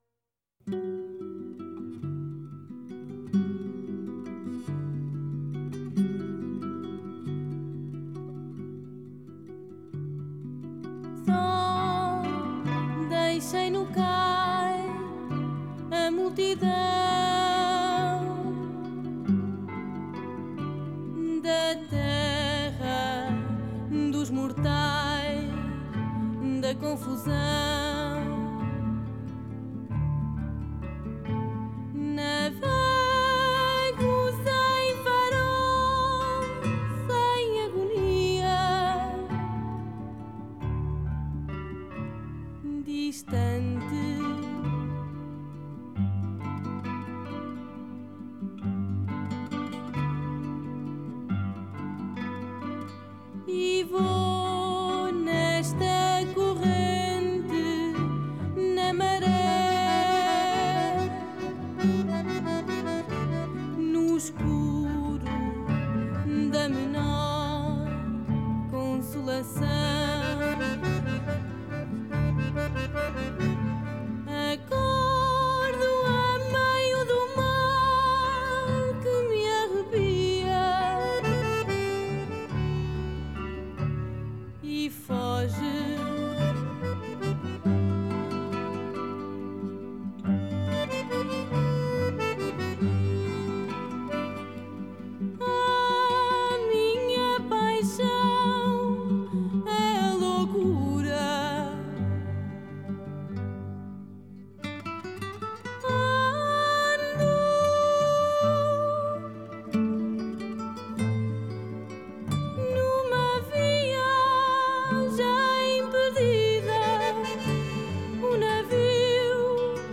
Genre: Fado, Ballad